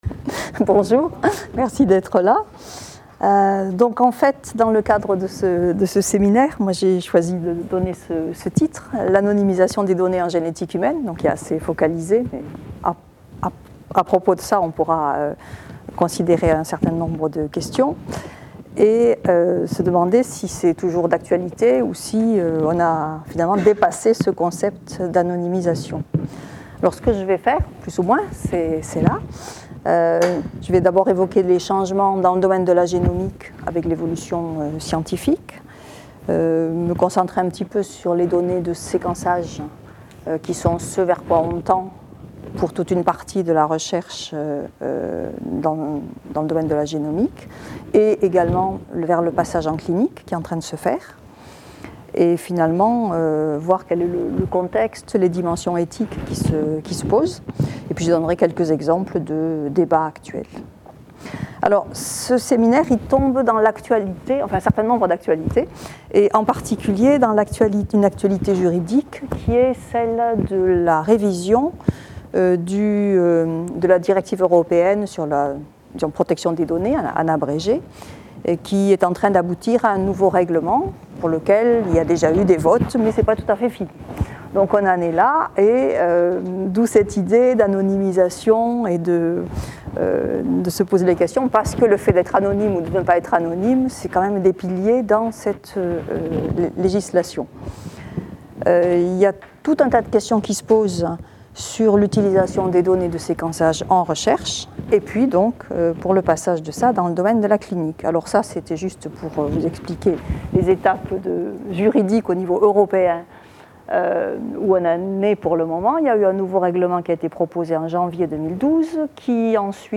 Intervention au séminaire formes de surveillance en médecine et santé publique.